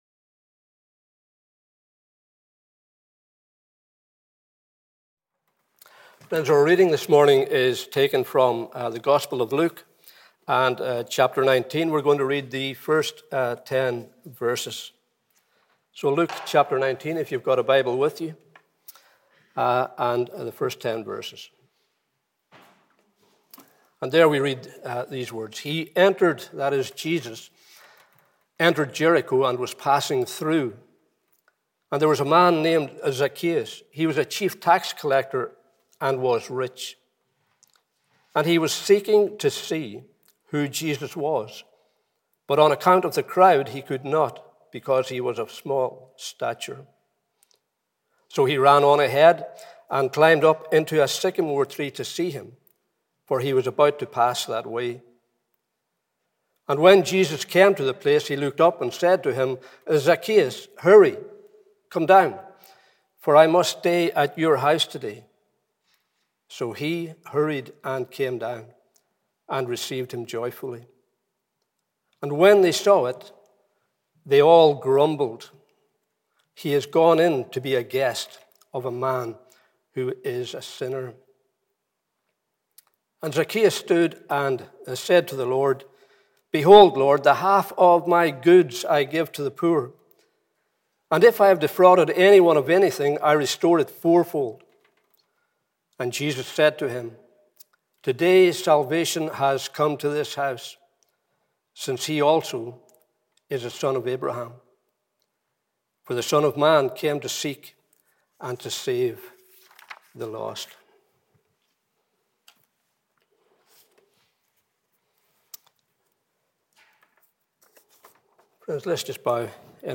Morning Service 18th April 2021